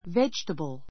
védʒ(ə)təbl